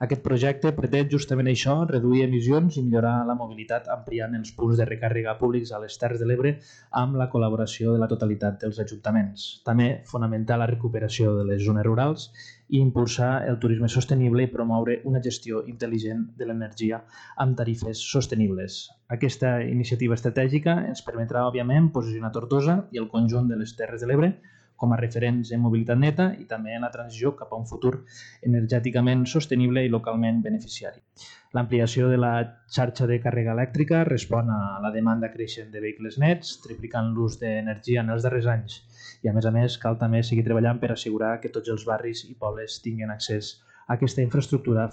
els grups al govern estan d’acord amb la proposta i asseguren que hi ha previst incrementar-los a través d’un projecte amb una inversió de 3 milions d’euros a través dels fons de transició nuclear. Ho ha explicat Víctor Grau, portaveu de movem-psc